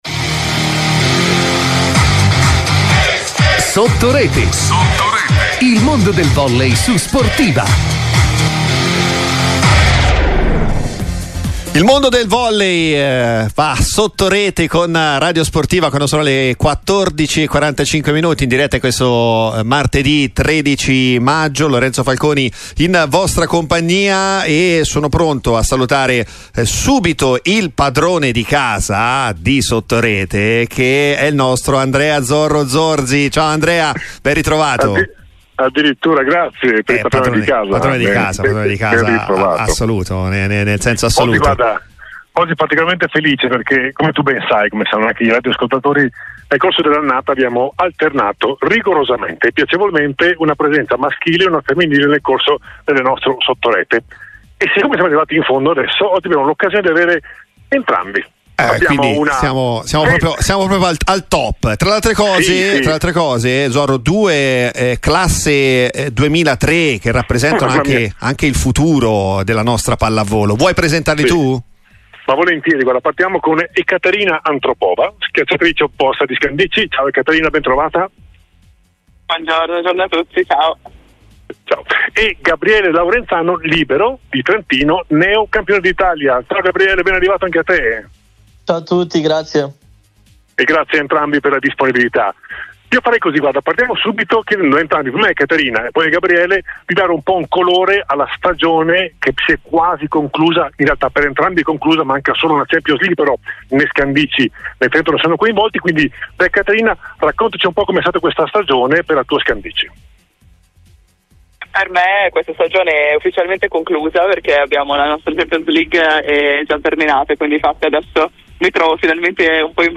Ascolta l'intervista integrale Trentino Volley Srl Ufficio Stampa